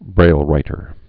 (brālrītər)